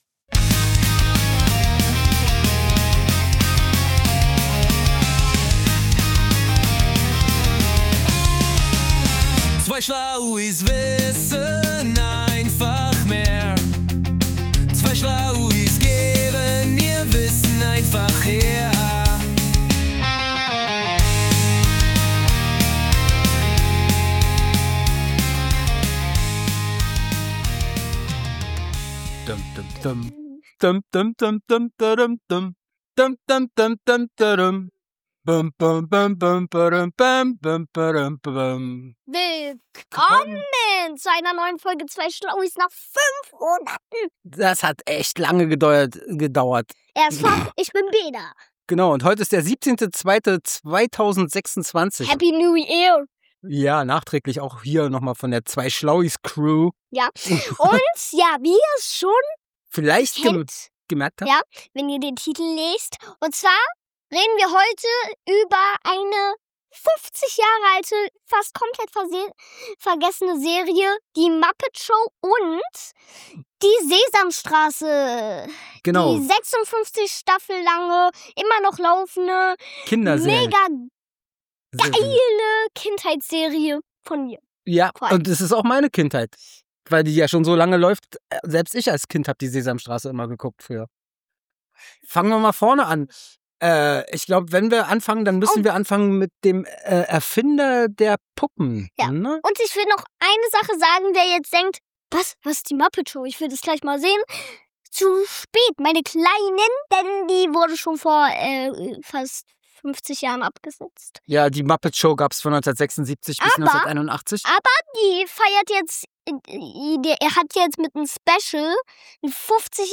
Das Gespräch spannt einen weiten Bogen von der technischen Entwicklung der ersten Kermit-Figur bis hin zu den pädagogischen Zielen und der internationalen Adaption dieser Klassiker. Die Sprecher reflektieren über nostalgische Kindheitserinnerungen und kündigen eine Rückkehr der Muppets für das Jahr 2026 an. Insgesamt dient der Dialog dazu, die handwerkliche Komplexität des Puppenspiels zu würdigen und die zeitlose Relevanz dieser Form der Unterhaltung für verschiedene Generationen hervorzuheben. dieser Podcast wurde mit  in Berlin erstellt.